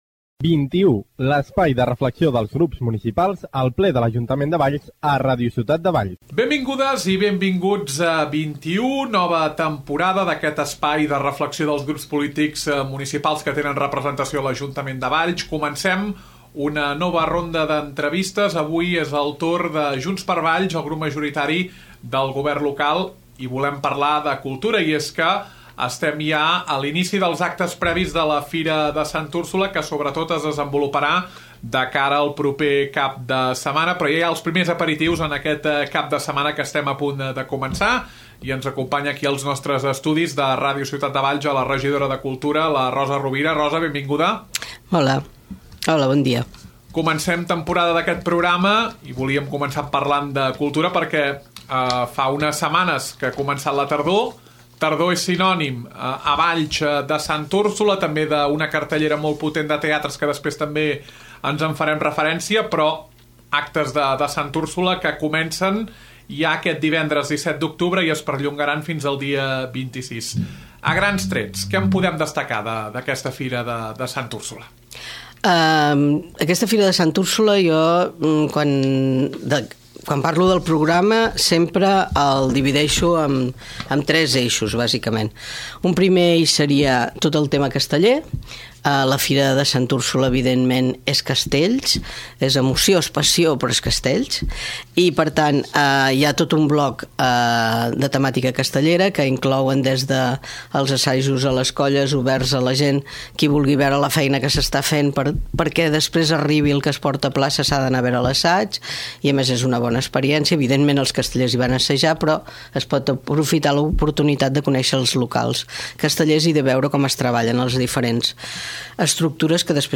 Entrevista a Rosa Rovira, regidora de Cultura.